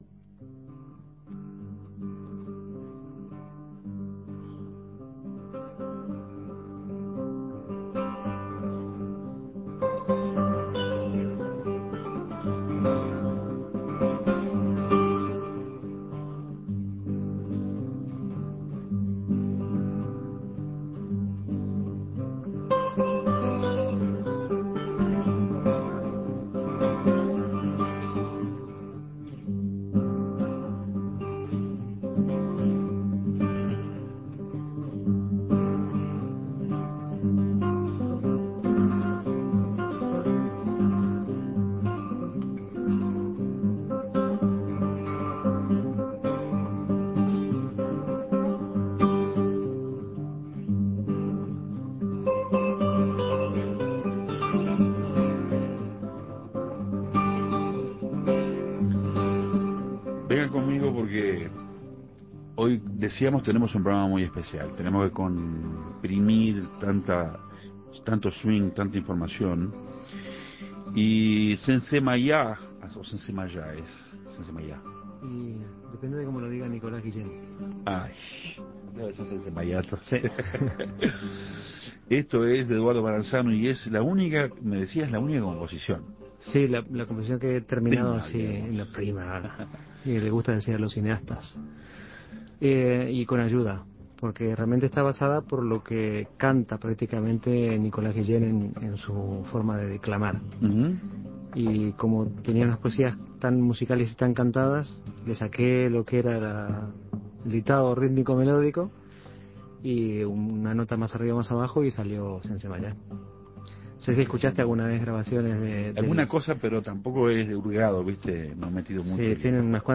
Cuerdas, manos, guitarra... en fonoplatea: